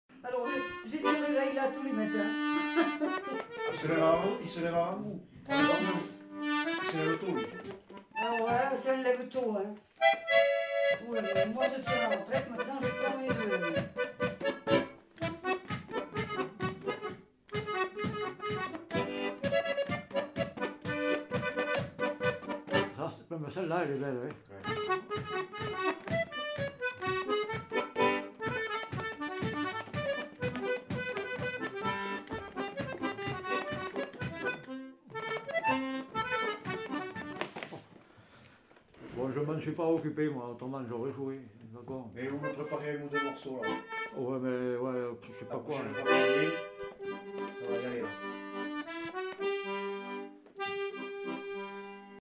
Aire culturelle : Quercy
Lieu : Bétaille
Genre : morceau instrumental
Instrument de musique : accordéon chromatique
Danse : marche (danse)